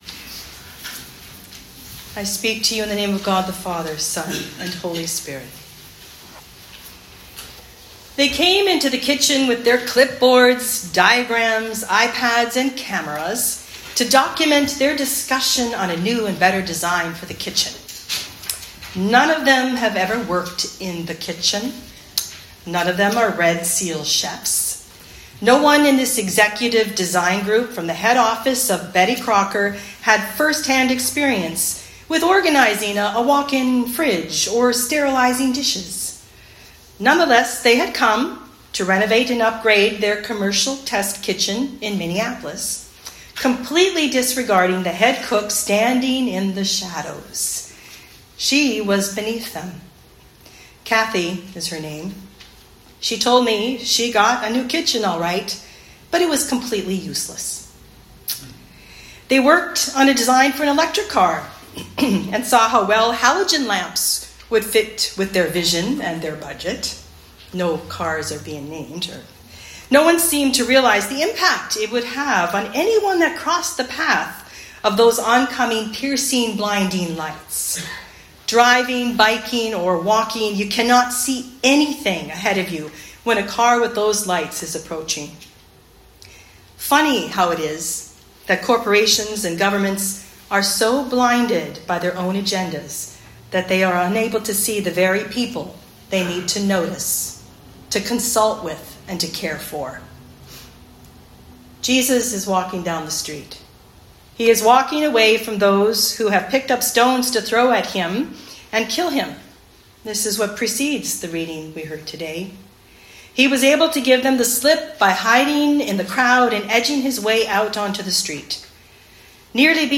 Sermons | Holy Trinity North Saanich Anglican Church